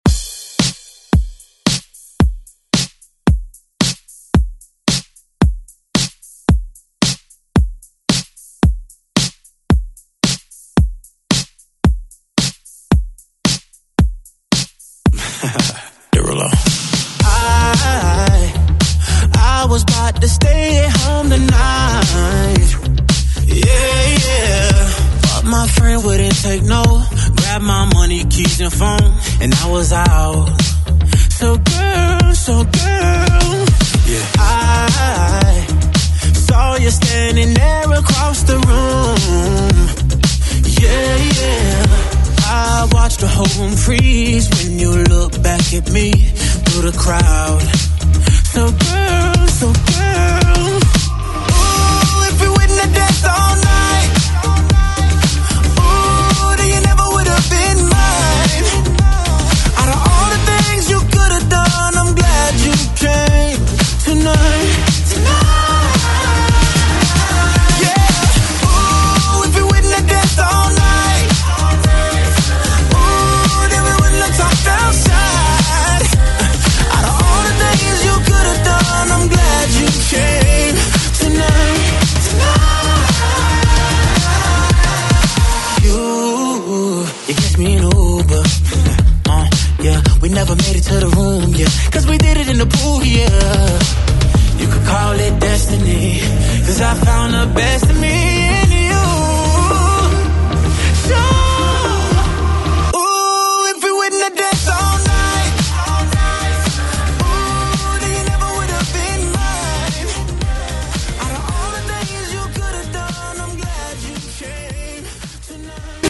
Electronic Pop Music
BPM: 112 Time